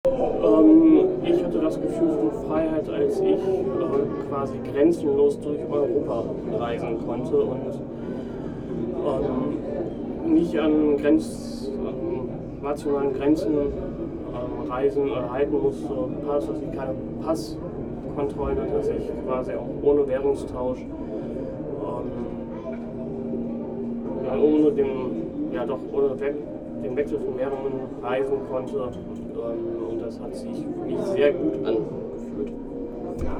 Standort der Erzählbox: